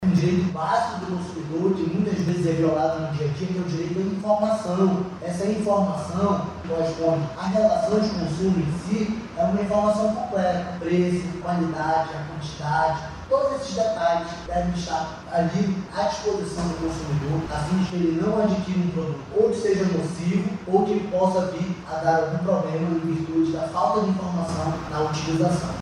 O diretor-presidente do Procon Amazonas, Jalil Fraxe, explica que há diferença nas regras de troca para compras presenciais e online.